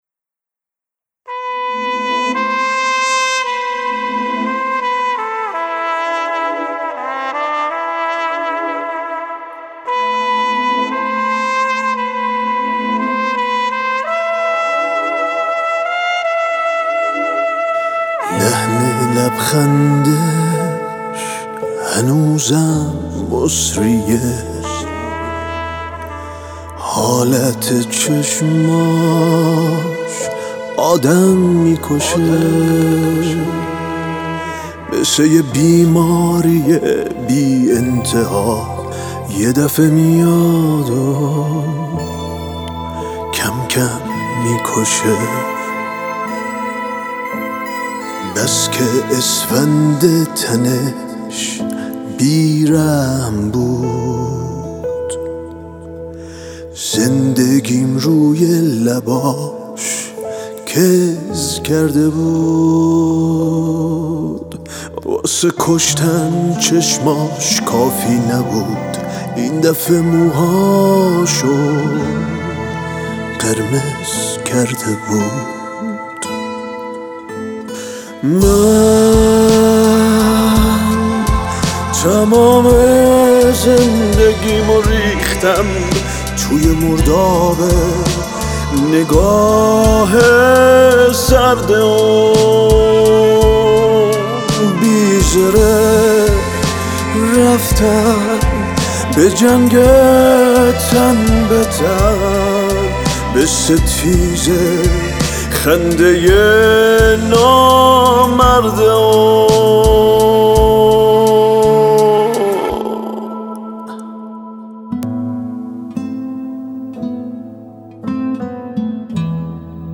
ترومپت: